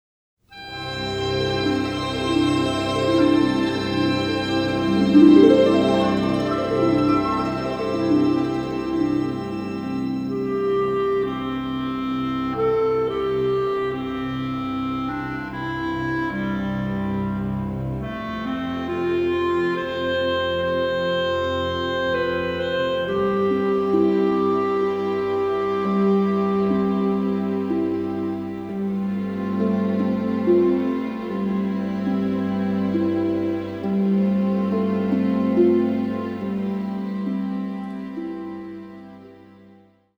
a delicate and poetic score
Remastered from the scoring session tapes